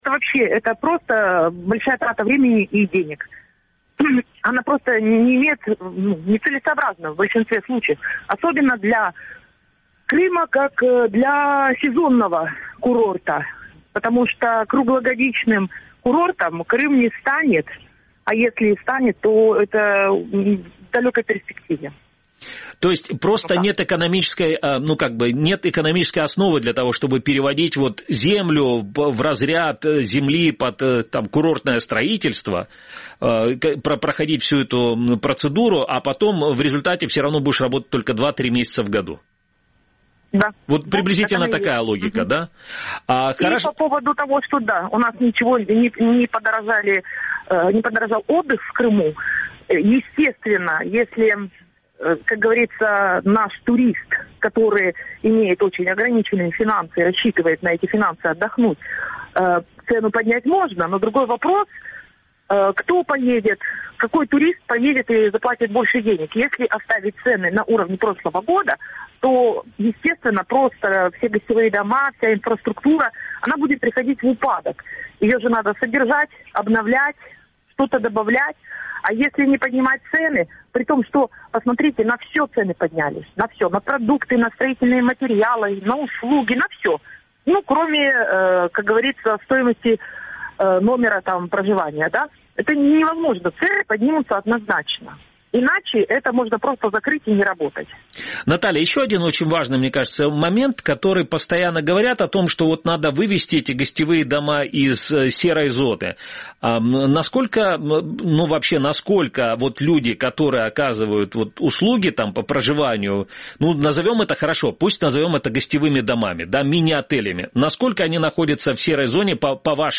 «Крымский вопрос» – главная тема дня. Выходит в эфир на Радио Крым.Реалии в будни.